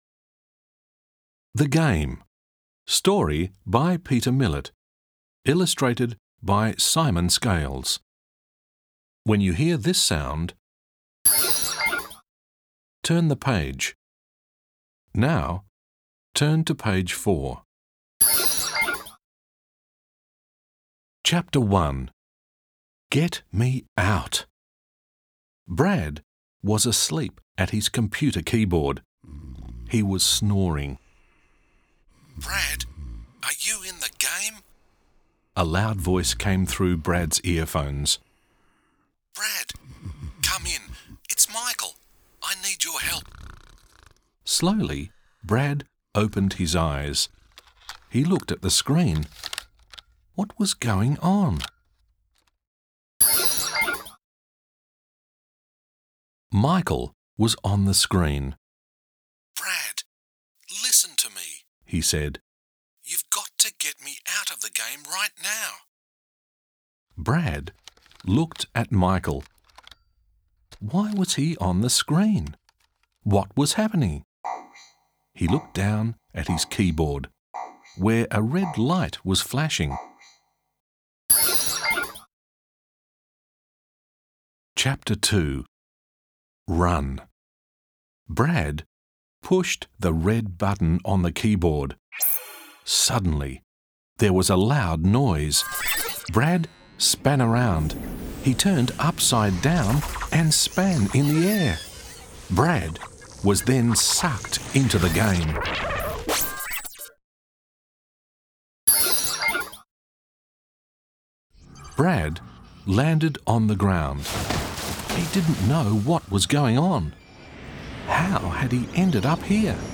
Short Story